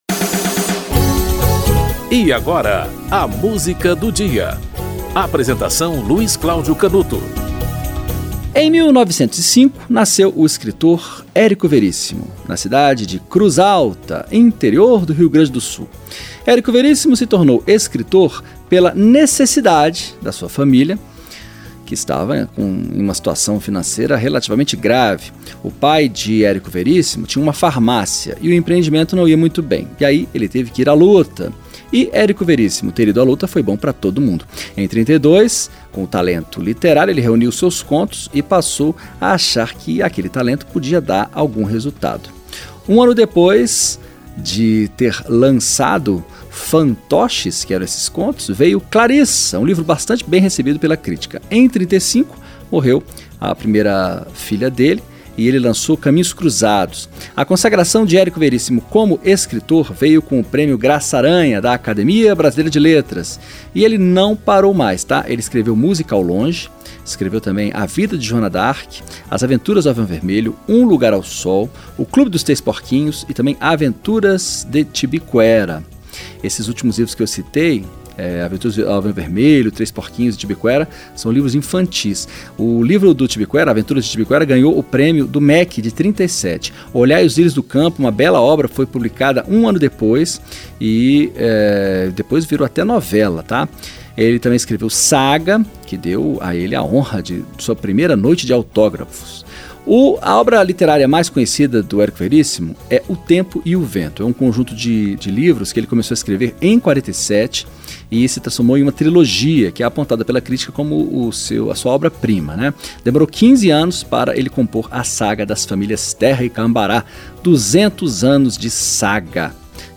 Coro, Danilo Caymmi e Tom Jobim - Passarim (Tom Jobim)